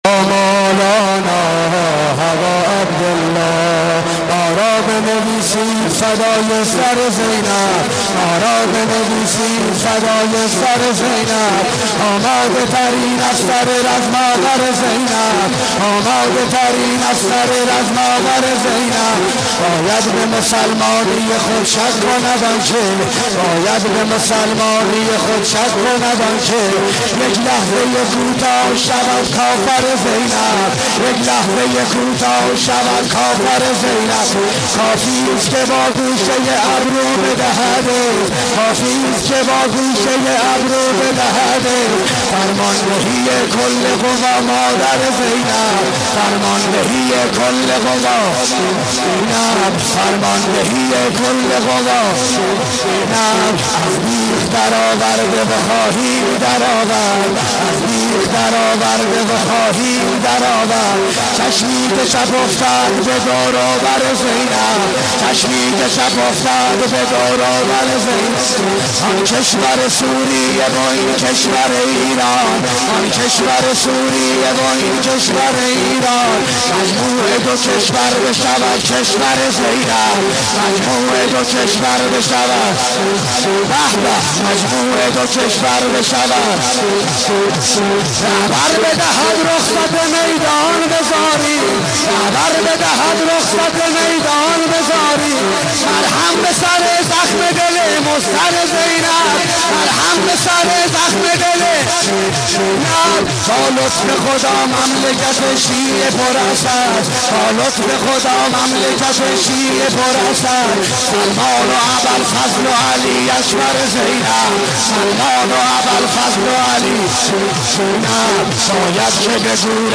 شورخوانی